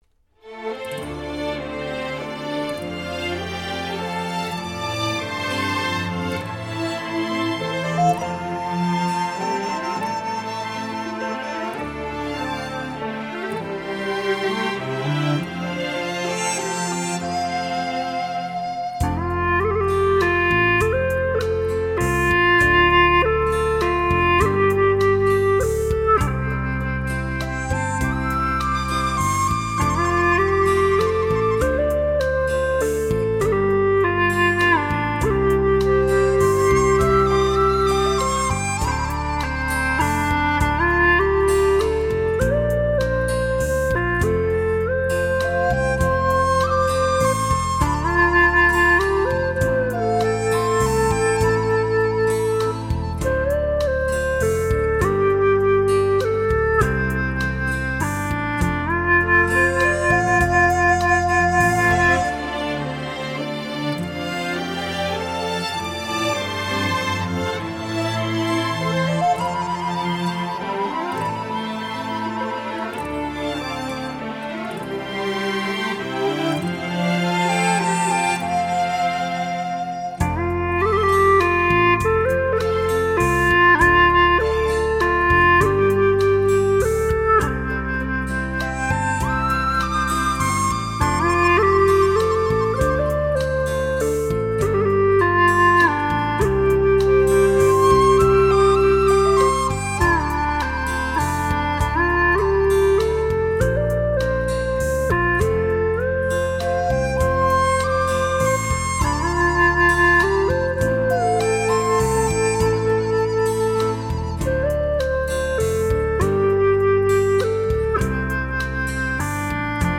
精彩的现代配器 原味的民族风格
古典与现代完美的结合
亲切婉转 温馨感人